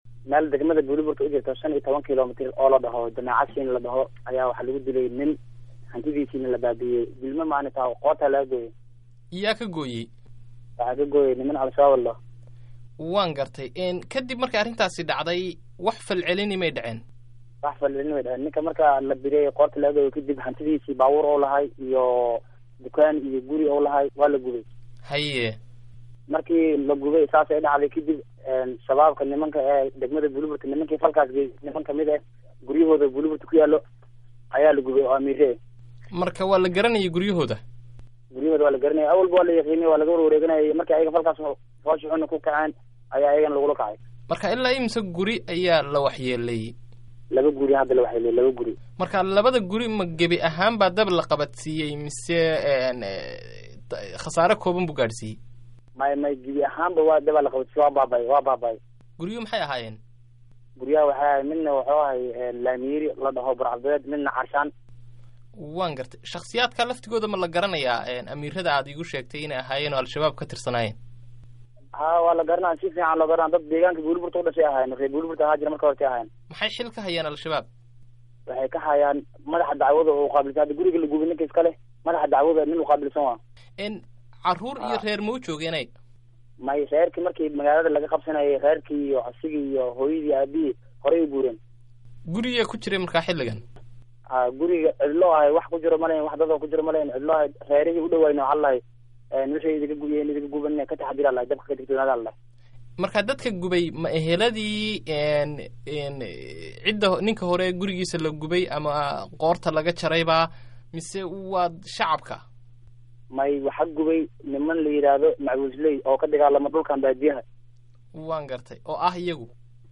Dhegayso: Waraysi ku saabsan guryo lagu gubay Buulo-Barde